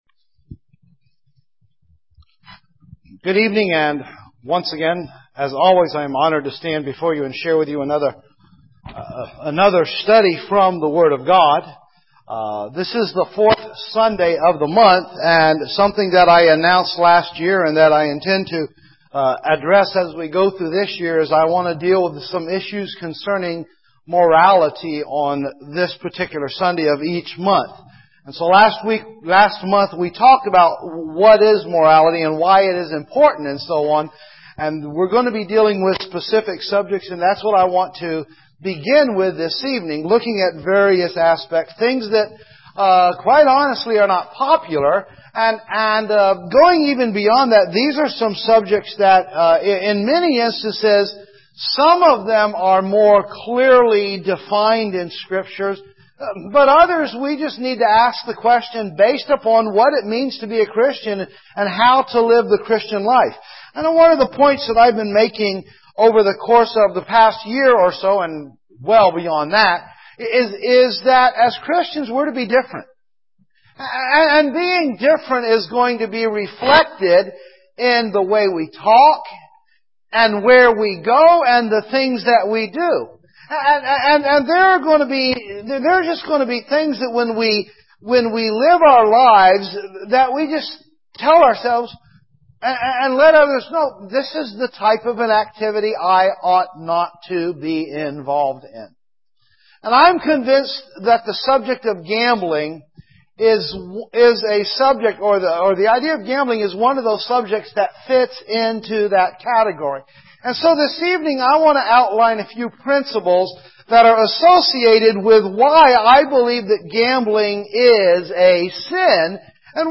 So here, from time to time, we will post sermons addressing the problems we face as Christians.